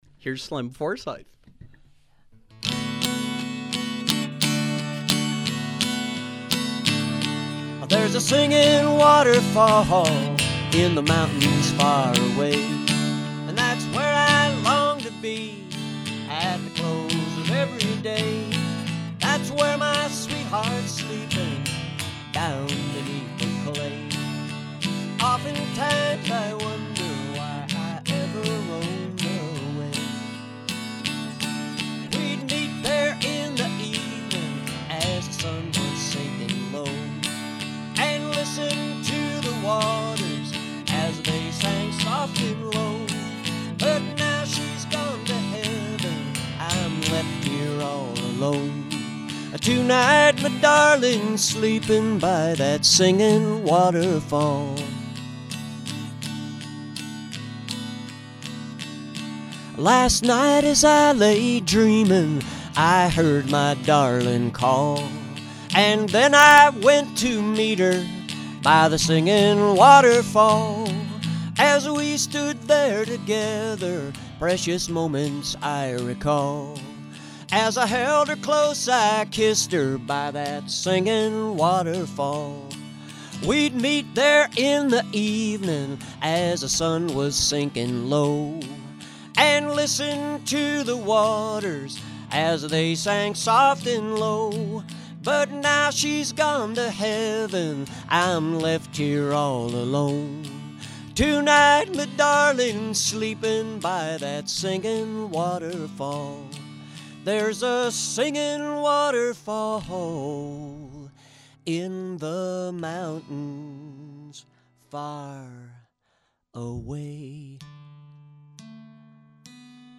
A special holiday performance